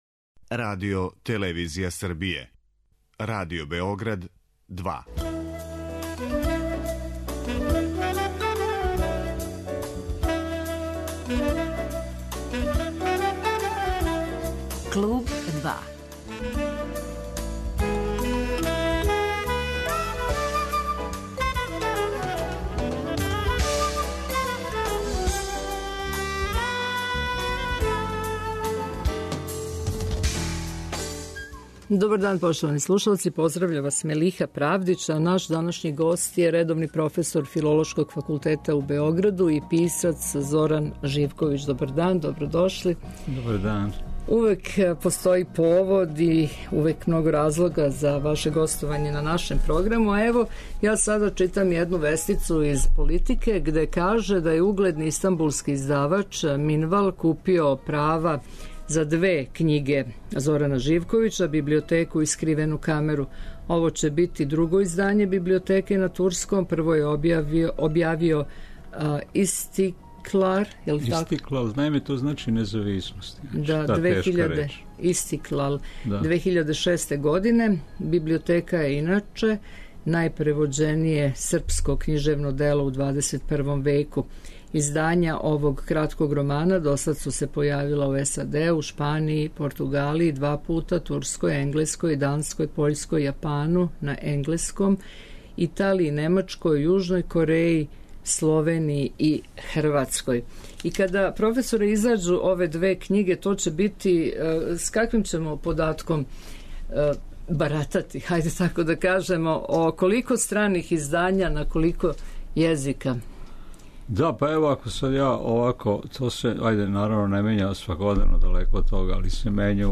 Гост 'Клуба 2' је писац Зоран Живковић